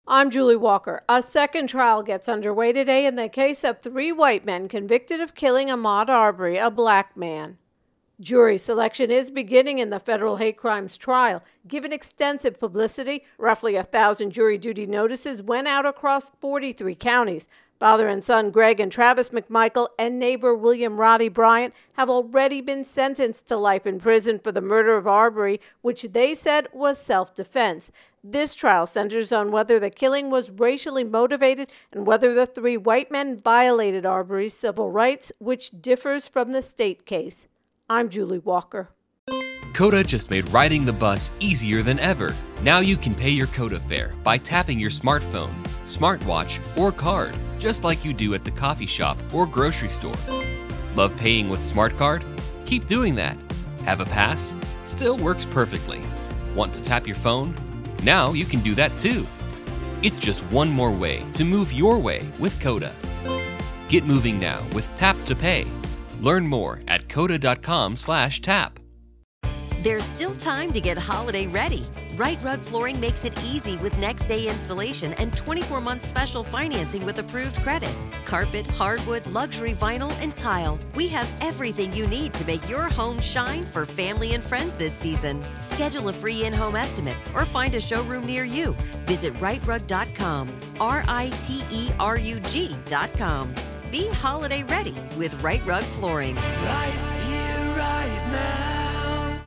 intro and voicer